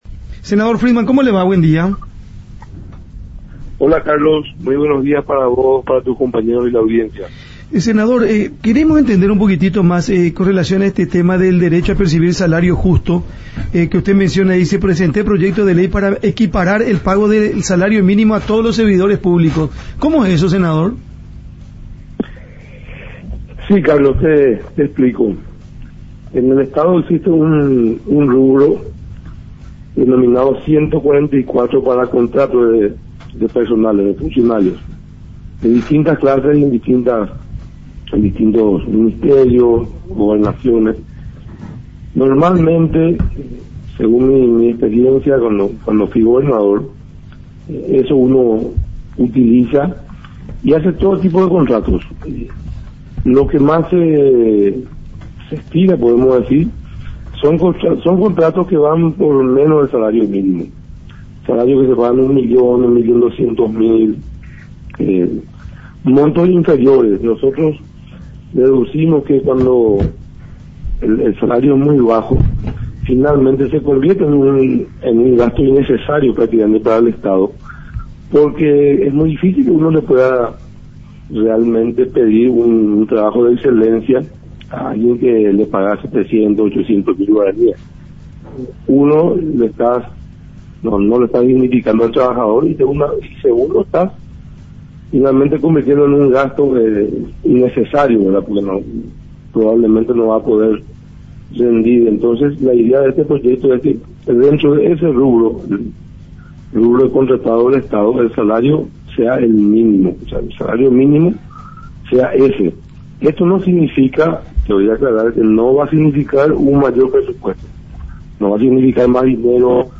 El senador de Colorado Añetete Rodolfo Friedmann explicó el proyecto de ley que pretende que todos los trabajadores tengan derecho a percibir salario justo.